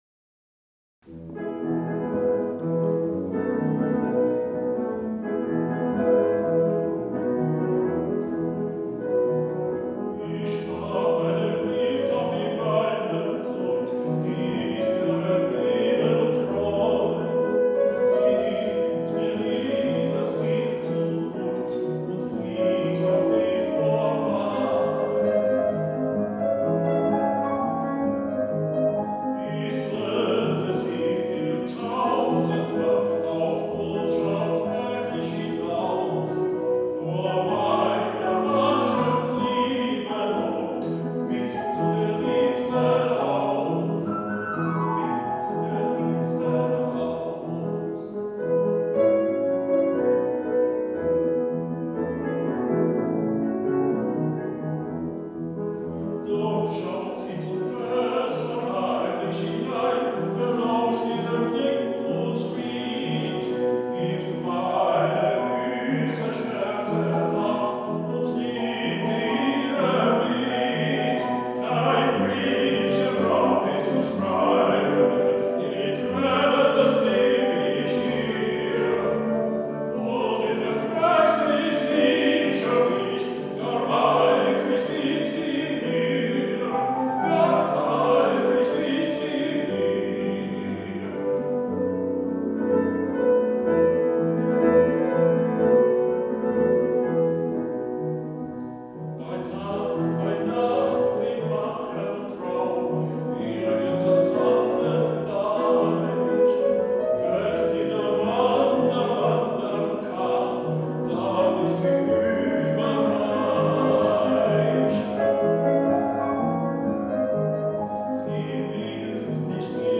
The song of the month is Die Taubenpost composed by Franz Schubert. This was recorded on August 5, 2013 in Vienna, with pianist